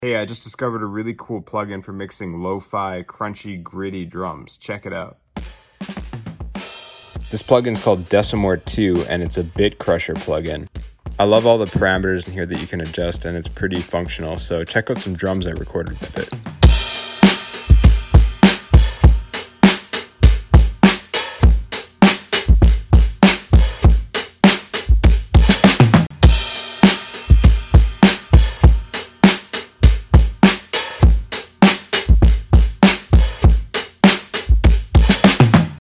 Great for getting dirty, gritty drum sounds.